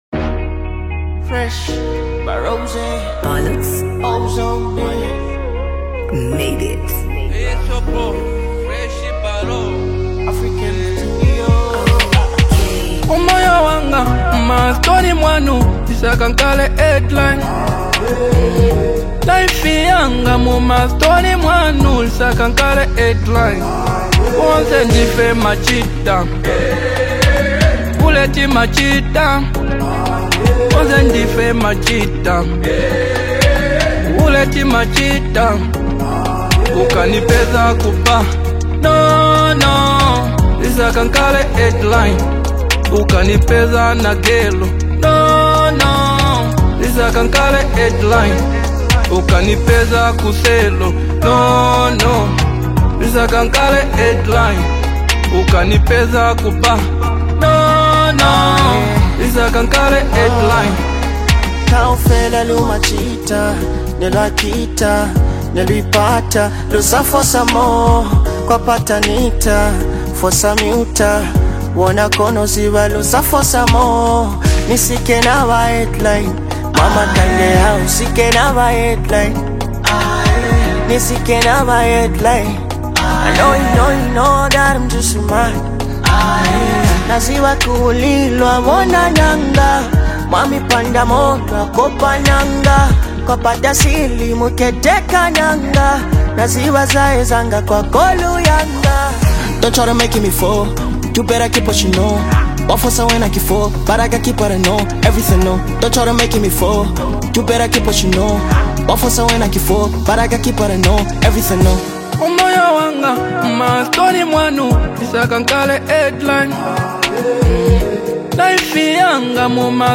Genre: Mbunga Songs